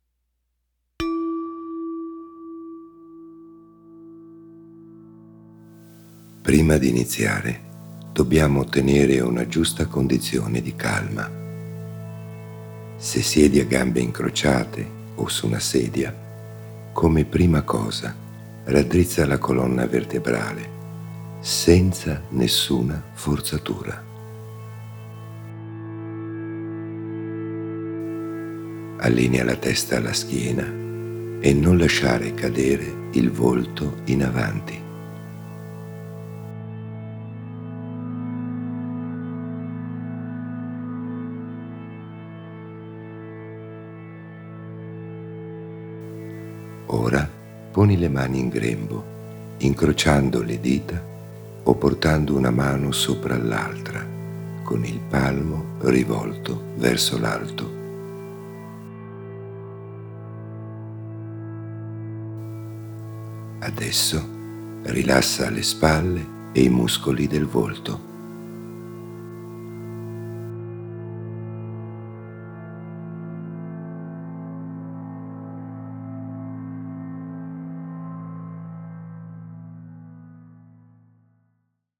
Una ricercata selezione e fusione di suoni e vibrazioni, che come un bisturi chirurgico producono un preciso e sapiente squarcio nelle mente ordinaria.
Demo-Meditazione-guidata-sul-suono.m4a